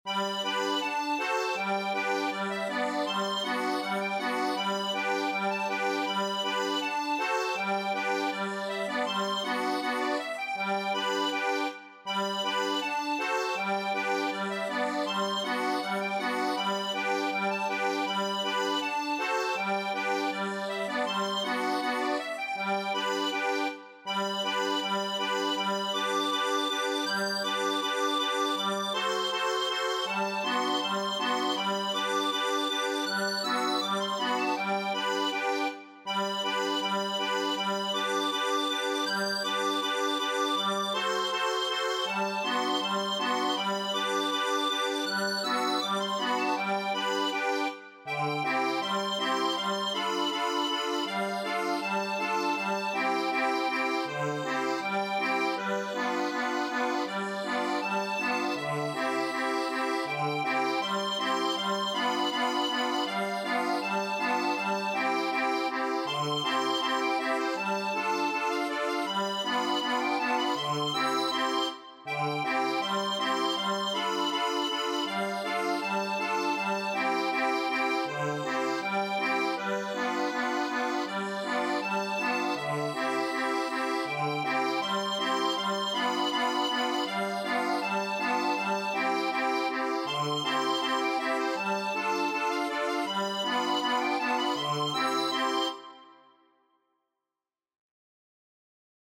Notenleser müssen also die Melodien transponieren, Tabulaturspieler können die Knopfnummern übernehmen, die Melodie erklingt dann in der Tonart des Instruments.
Speziell für Deutsche CG-Konzertina: